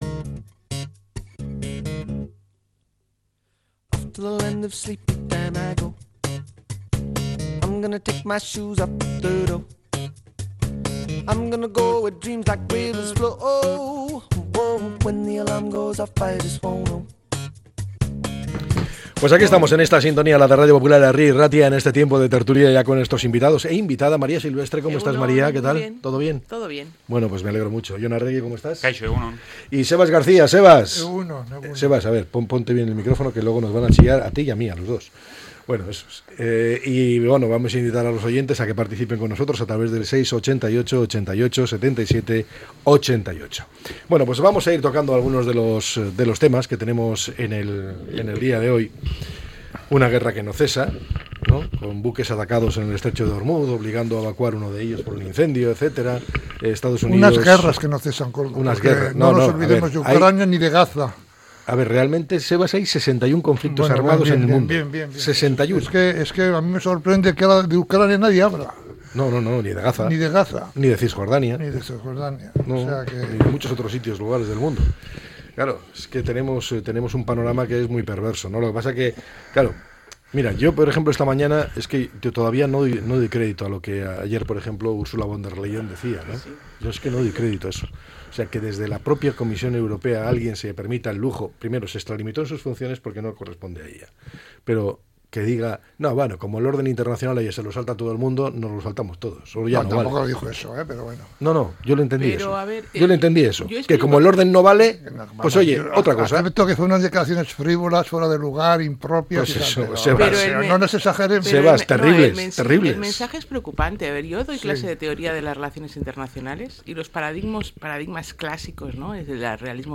La Tertulia 11-03-26.